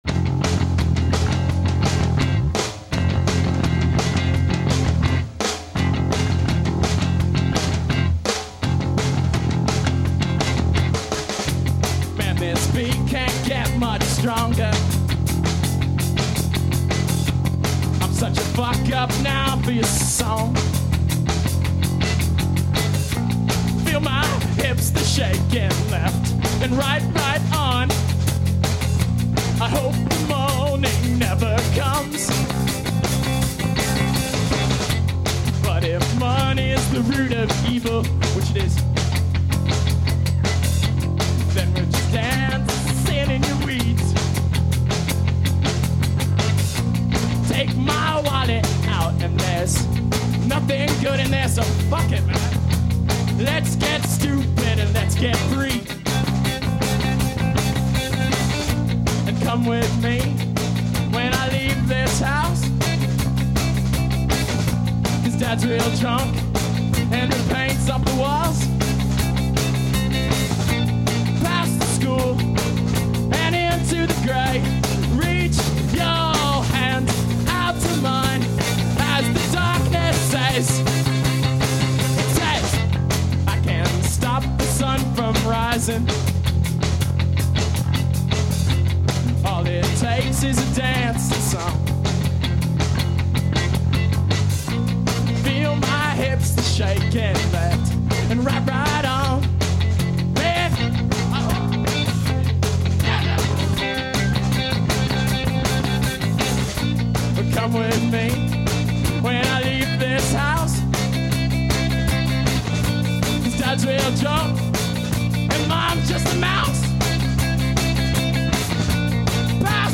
more brit-poppy.